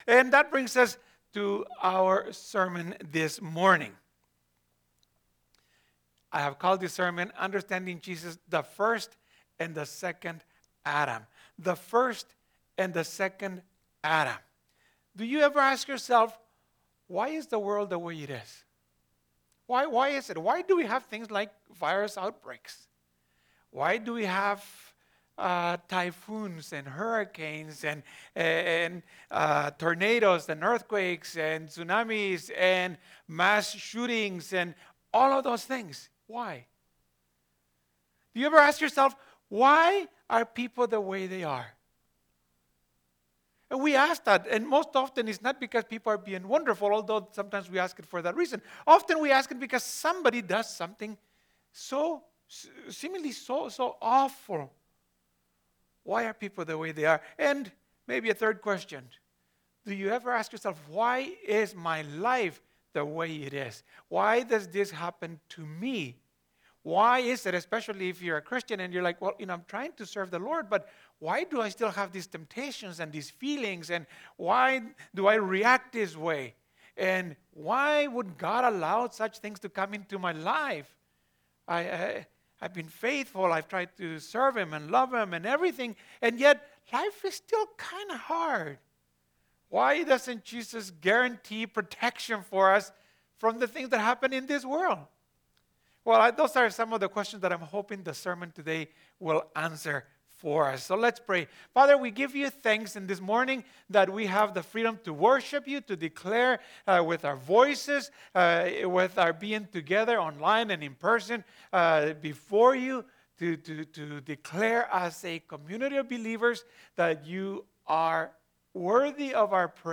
This is the second sermon in the 2021 Lent Sermon Series.